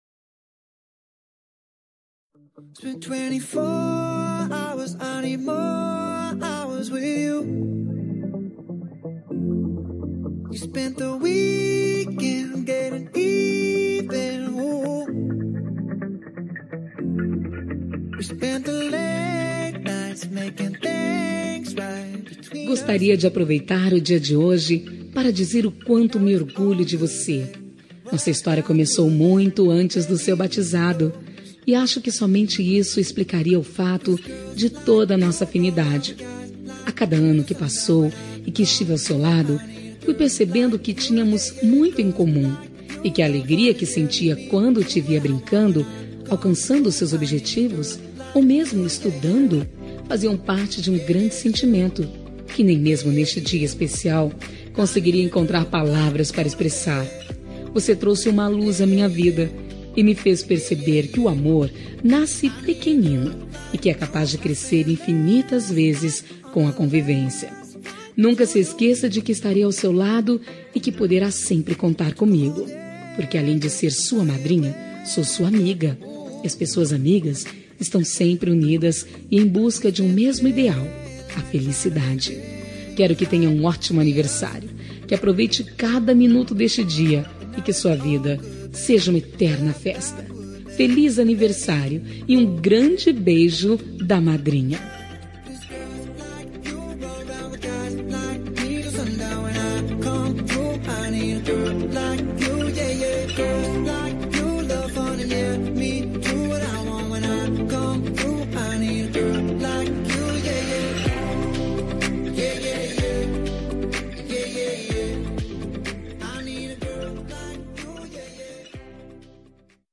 Aniversário de Afilhada – Voz Feminina – Cód: 4214